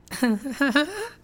nervous giggle